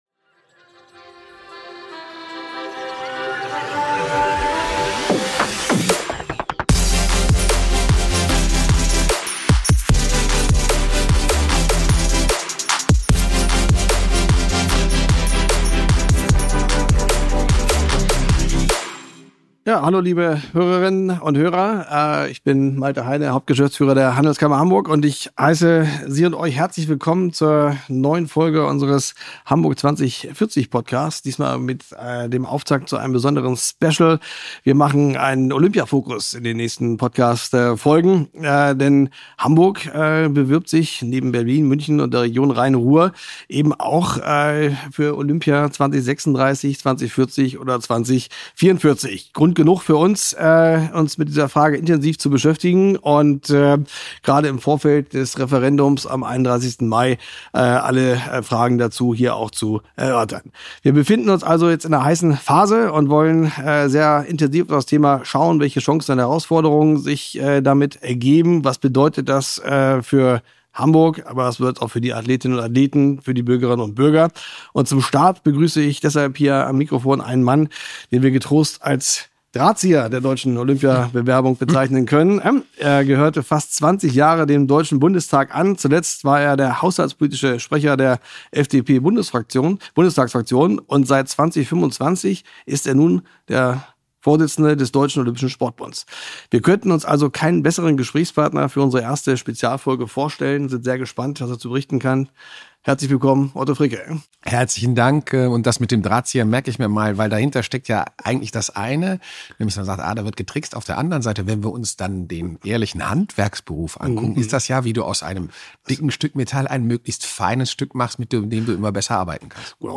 Olympia als Zukunftsentscheidung – im Gespräch mit DOSB‑Vorstand Otto Fricke ~ Hamburg 2040 Podcast